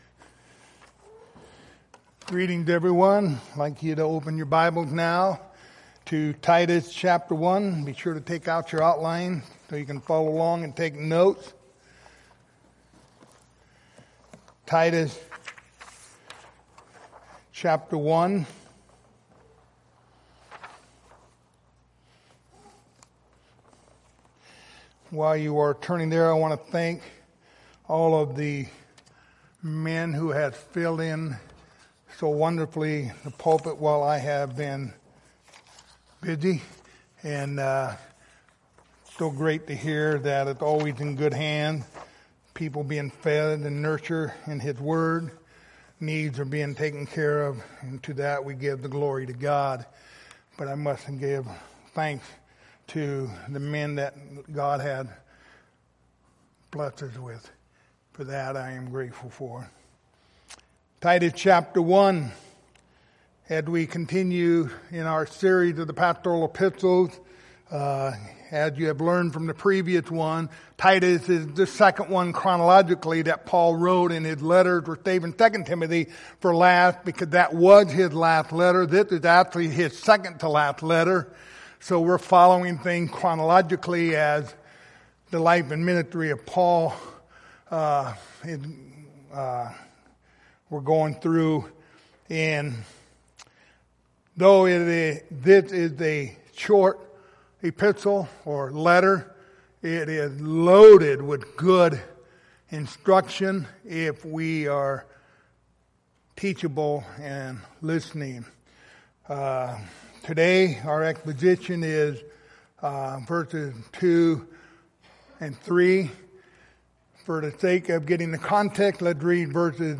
Passage: Titus 1:2-3 Service Type: Sunday Morning